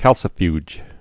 (kălsə-fyj)